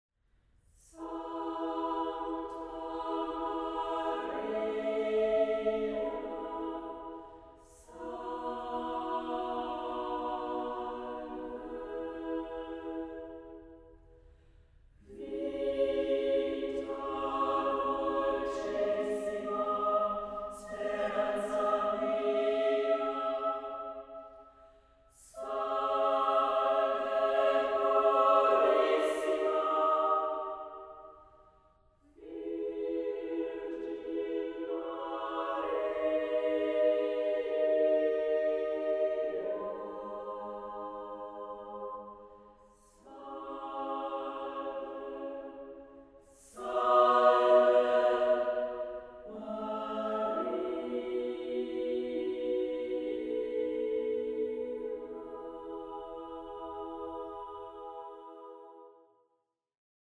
Chamber choir